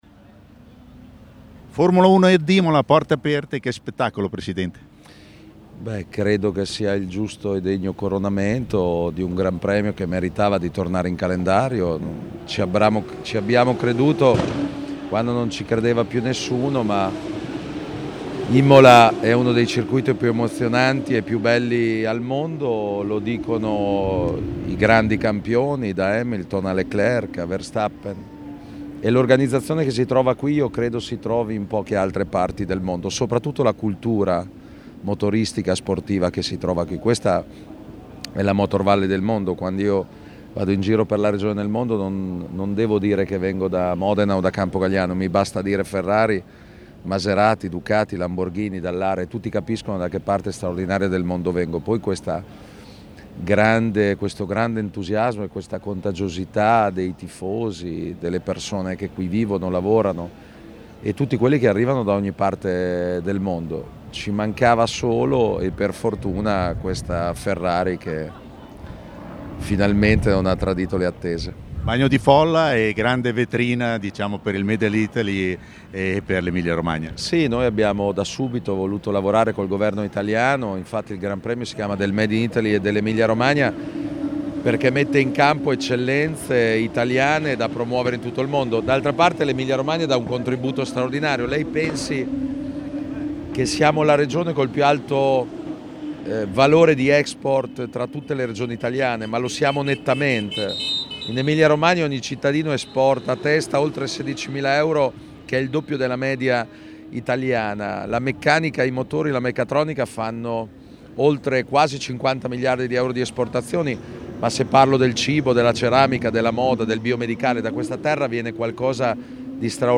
Stefano Bonaccini, Governatore della Regione Emilia Romagna:
Stefano-Bonaccini-Governatore-Emilia-Romagna.mp3